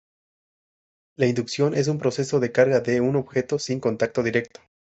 Read more Noun Verb objetar to object to demur Read more Frequency B2 Hyphenated as ob‧je‧to Pronounced as (IPA) /obˈxeto/ Etymology From Latin obiectum.